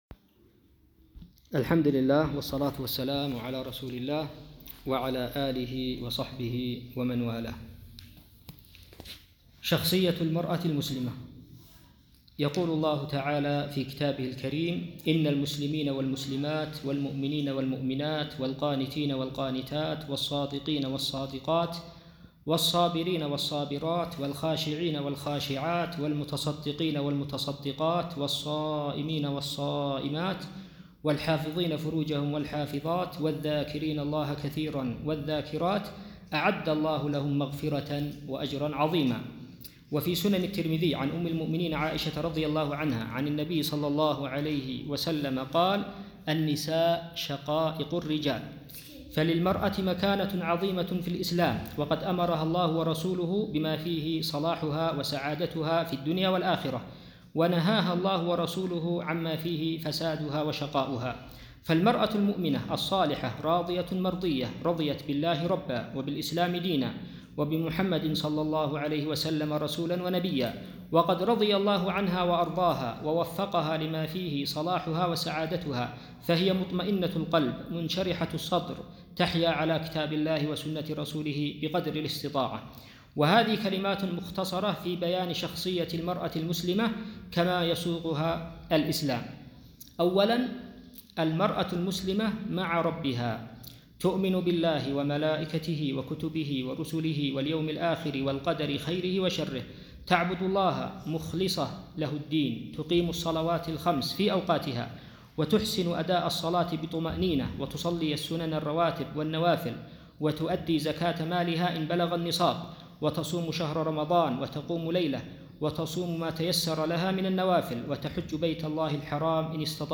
بيان شخصية المرأة المسلمة (محاضرة مهمة للنساء)